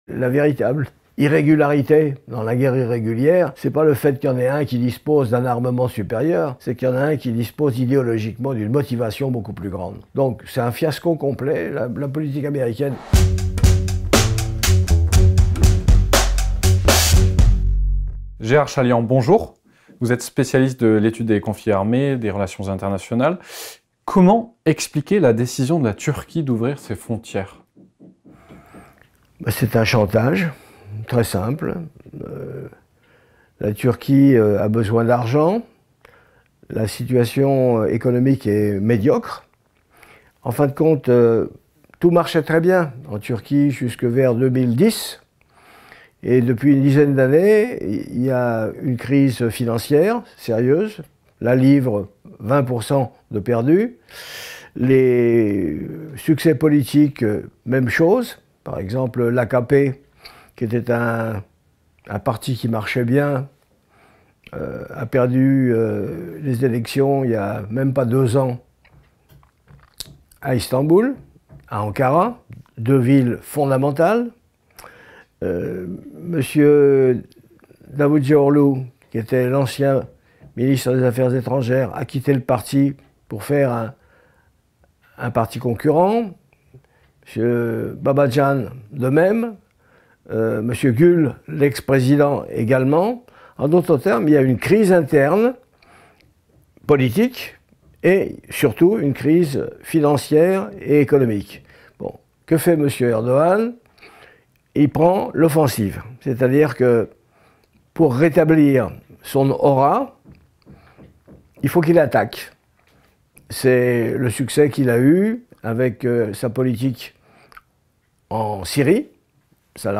Suite à la perte de trente-trois soldats à Idlib le 27 février, Ankara a décidé d’ouvrir aux migrants sa frontière avec la Grèce. Alors que la situation en Syrie est toujours tendue, Erdogan rencontre Poutine à Moscou. Pour y voir plus clair dans ce Moyen-Orient compliqué, Sputnik a interrogé Gérard Chaliand, spécialiste de l’étude des conflits armés et des relations internationales et stratégiques